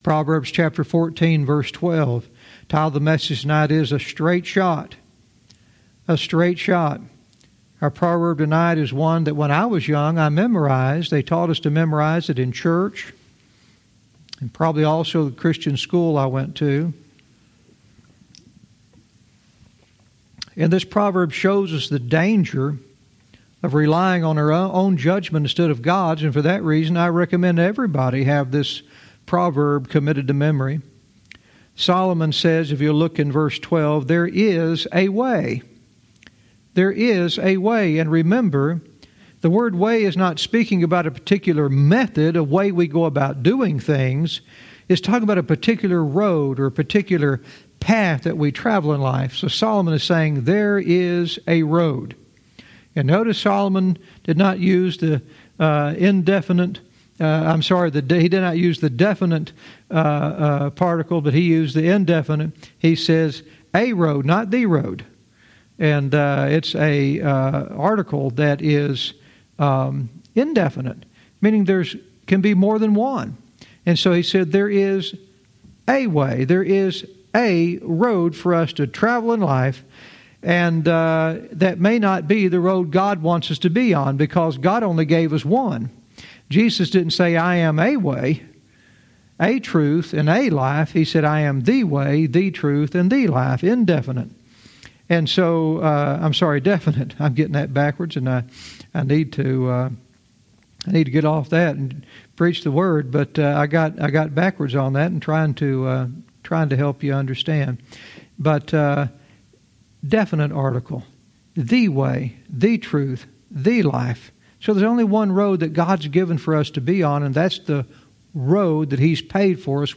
Verse by verse teaching - Proverbs 14:12 "A Straight Shot"